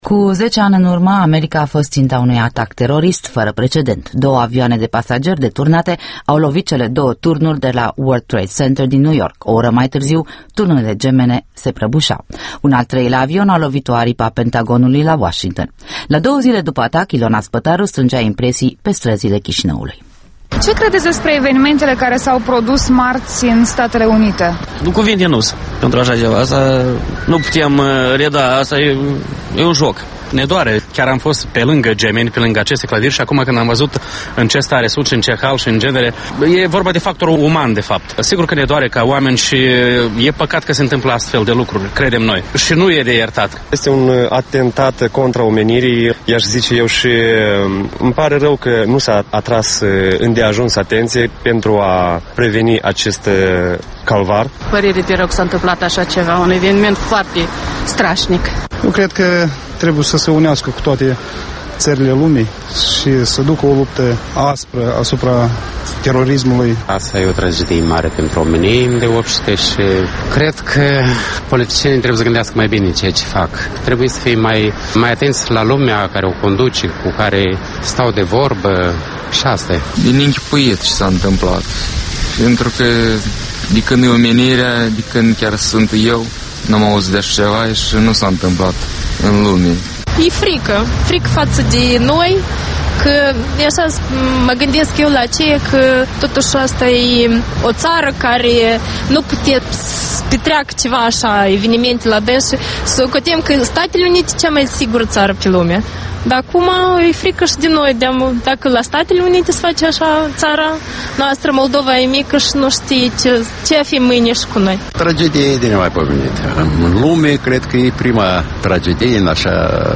Un vox populi pe străzile Chişinăului.